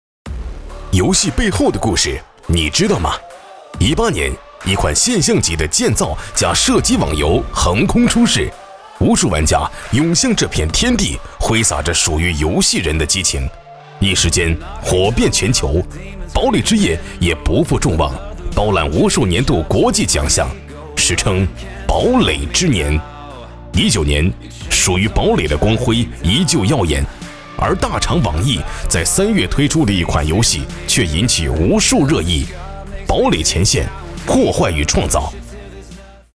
男国373_动画_游戏_游戏解说配音.mp3